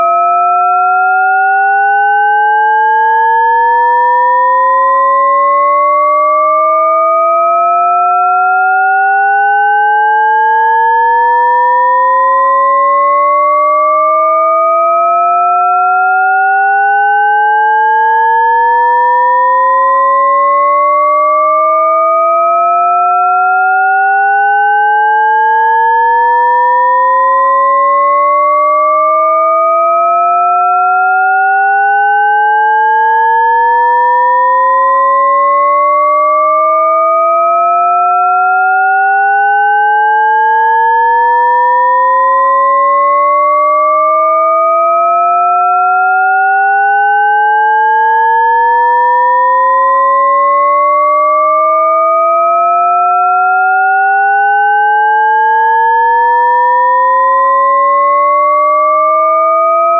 Son montant indéfiniment
Il s'agit d'un programme en Python qui génère un son qui semble monter indéfiniment. Il génère un son (sap-son_de_base.wav) de 16 à 4096 Hz de volume croissant jusqu'à 256Hz puis décroissant. Le programme superpose plusieurs fois ce son en décalant à chaque fois d'une durée correspondant à la montée d'une octave.